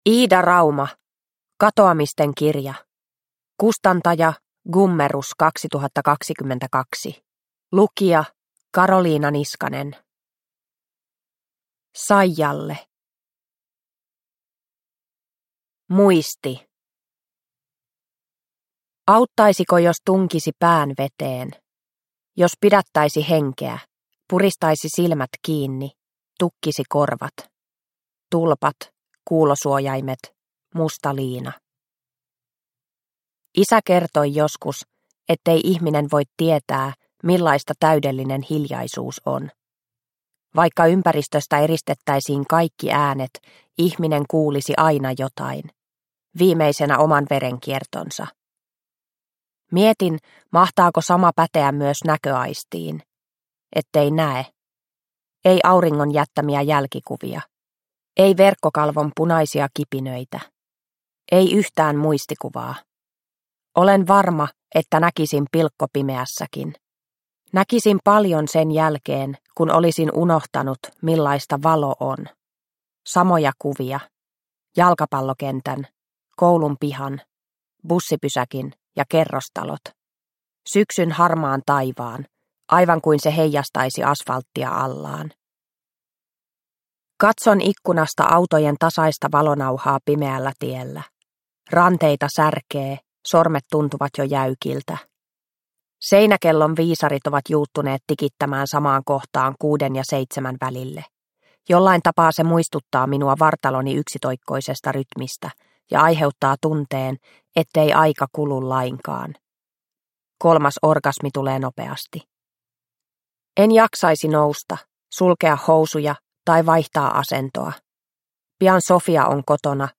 Katoamisten kirja – Ljudbok – Laddas ner